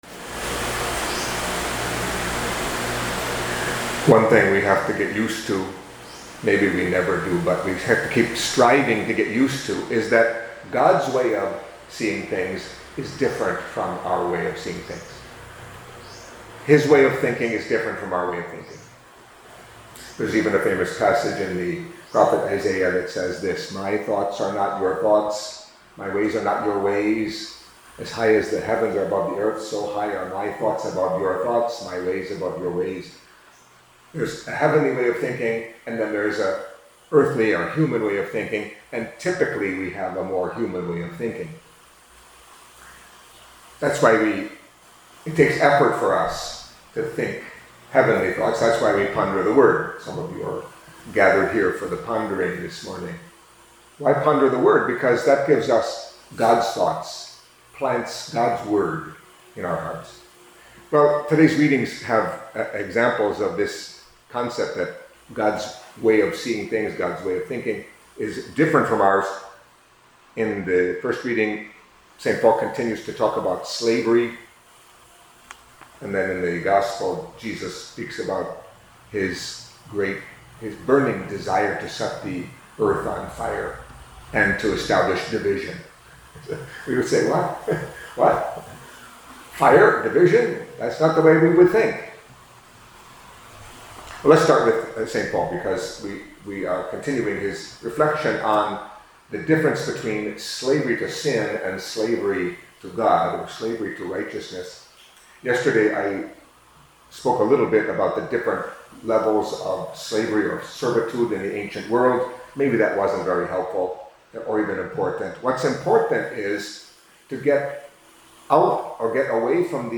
Catholic Mass homily for Thursday of the Twenty-Ninth Week in Ordinary Time